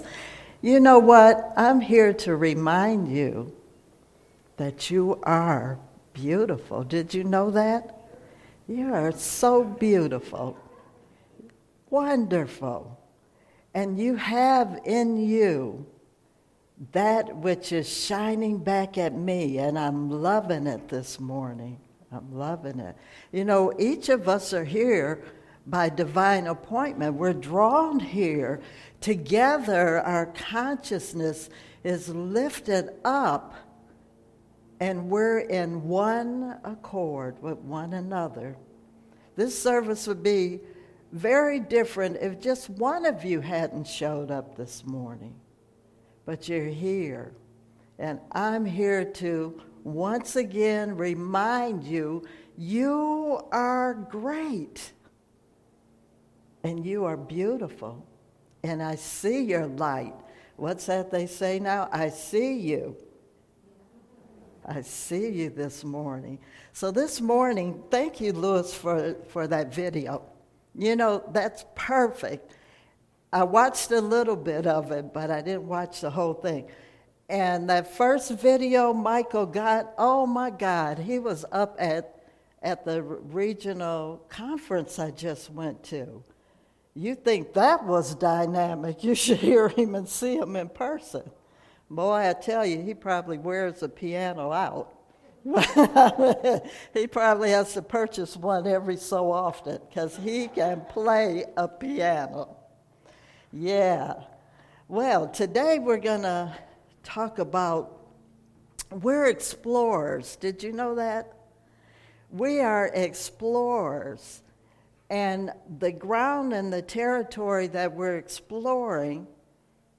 Series: Sermons 2019